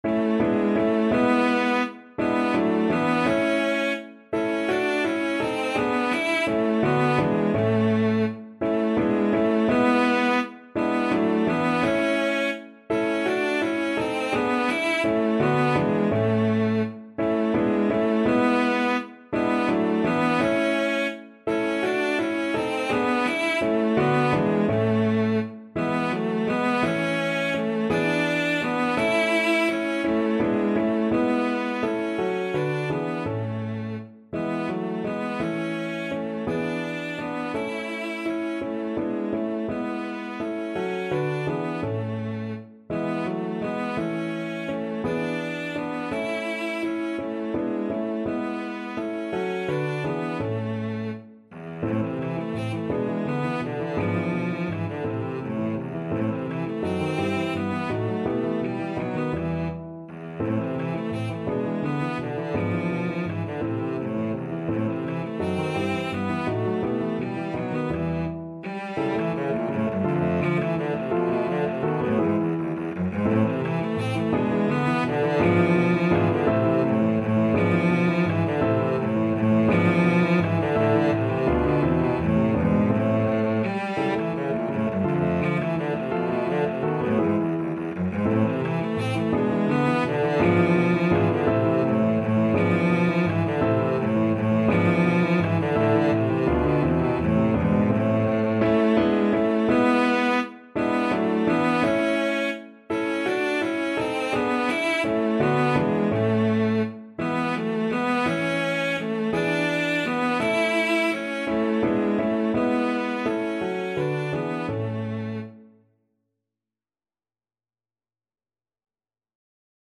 3/8 (View more 3/8 Music)
Lustig (Happy) .=56
Classical (View more Classical Cello Music)